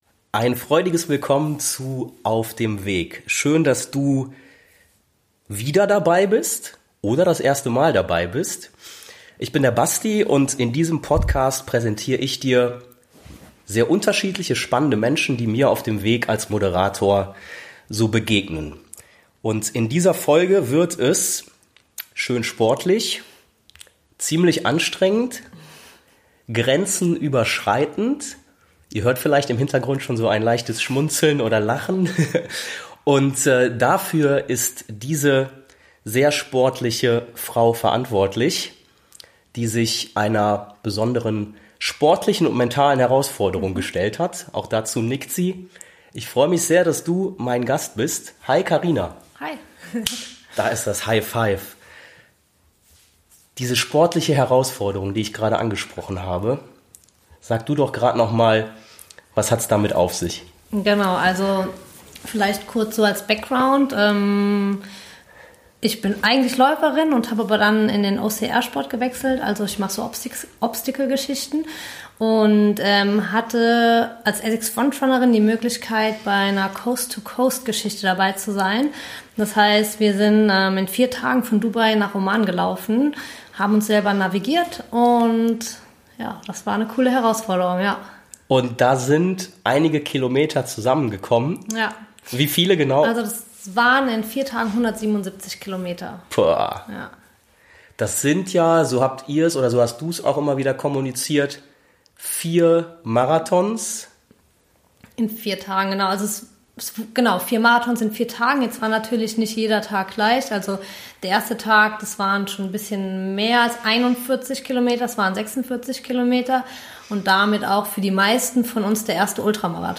Dieses von mir gewünschte Gespräch erlebst Du in dieser Podcast-Folge mit.